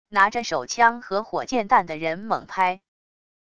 拿着手枪和火箭弹的人猛拍wav音频